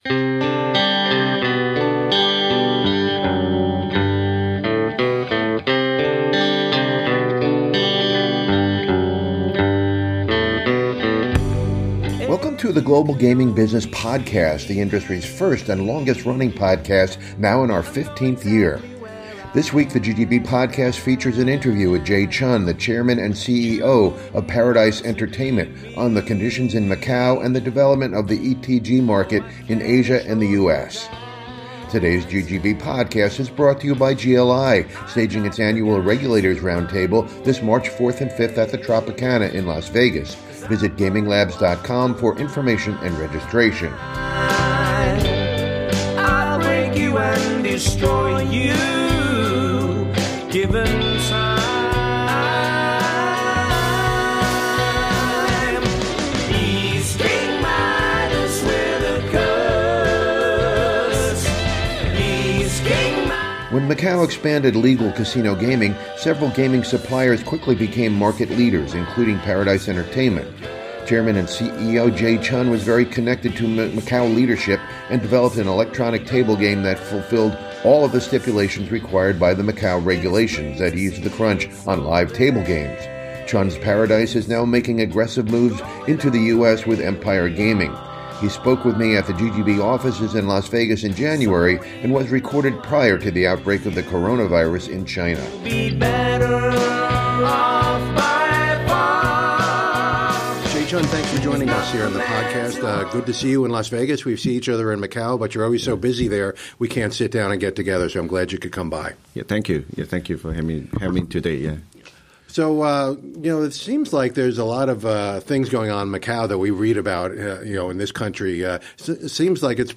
(This interview was conducted prior to the outbreak of the coronavirus in China.)